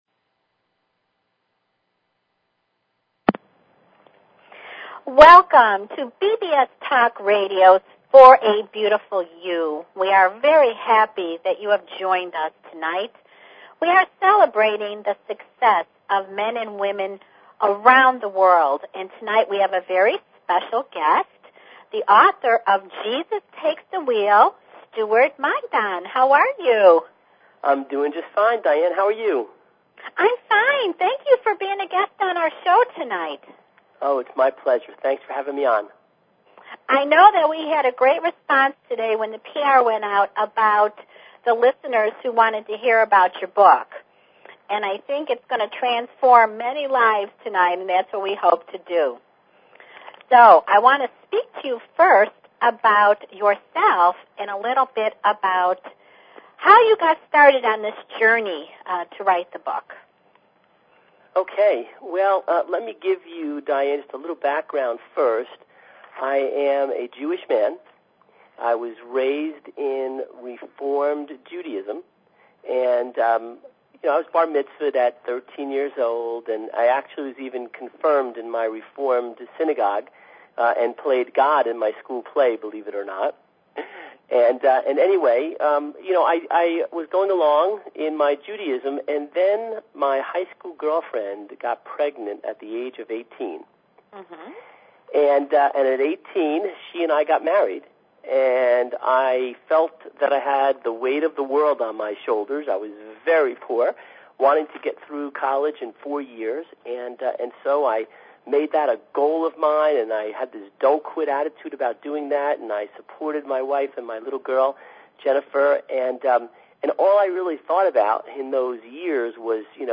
Talk Show Episode, Audio Podcast, For_A_Beautiful_You and Courtesy of BBS Radio on , show guests , about , categorized as
Presented by the award winning *Miss *Mrs. U.S. Beauties National Pageant Organization this live weekly one hour show gives a fresh perspective concerning the pageant, fashion, beauty and celebrity industry.